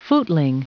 Prononciation du mot footling en anglais (fichier audio)
Prononciation du mot : footling